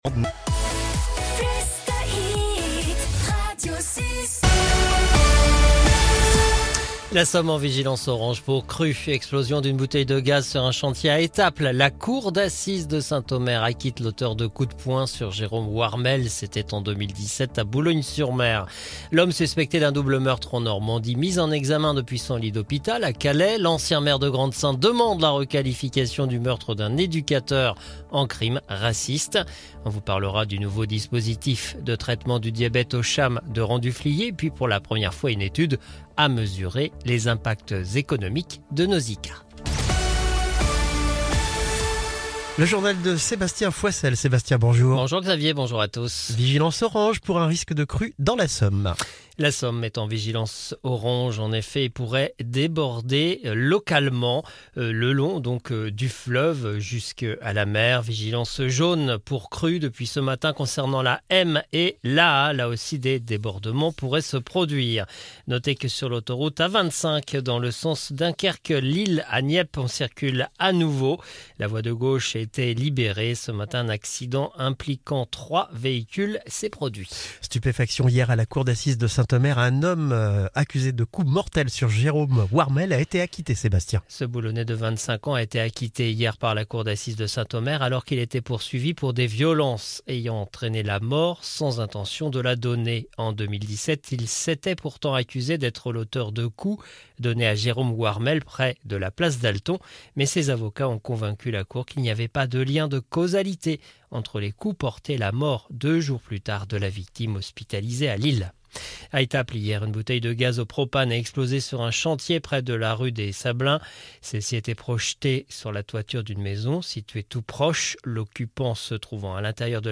Le journal du 30 janvier 2025